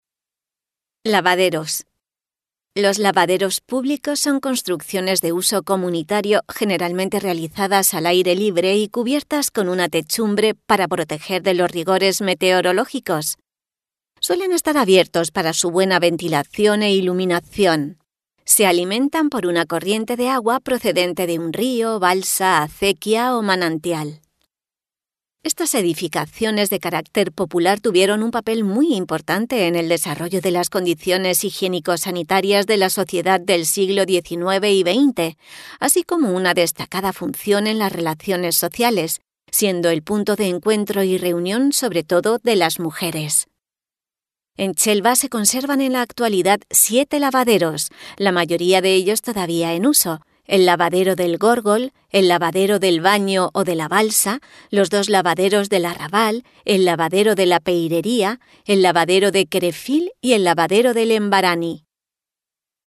Audioguía Lavaderos: